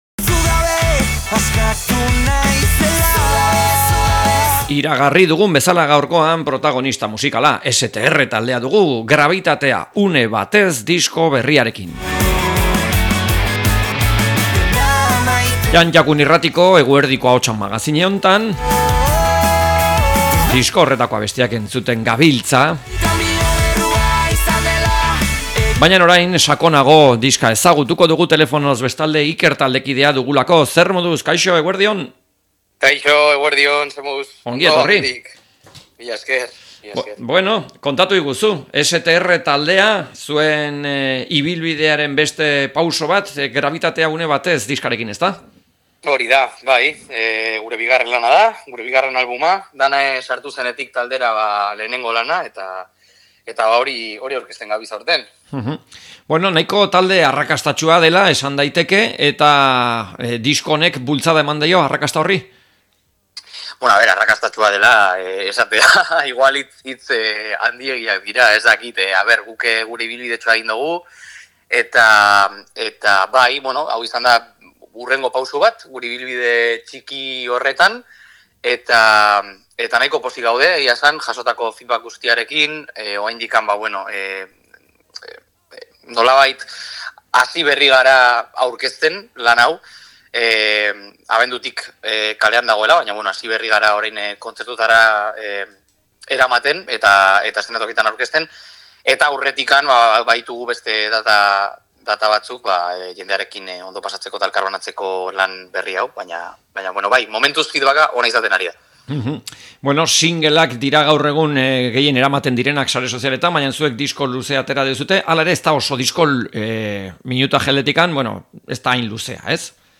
STR-ri elkarrizketa